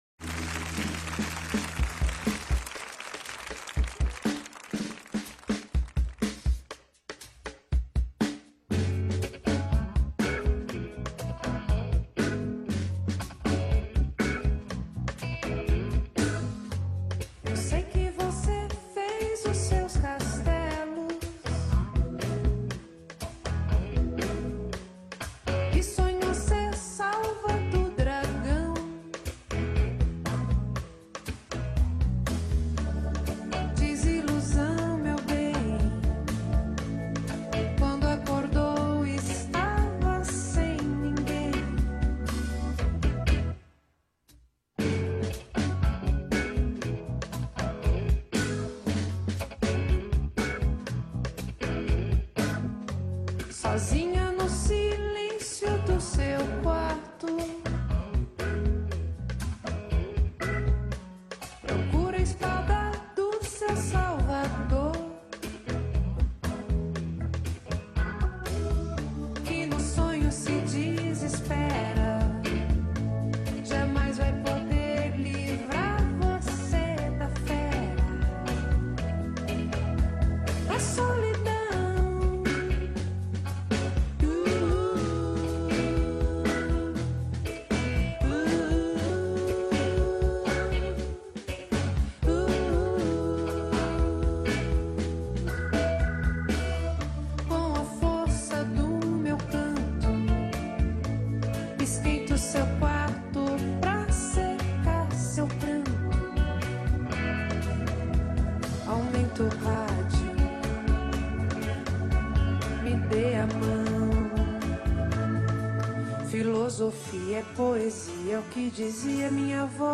bateria
baixo
guitarra
trumpete
teclados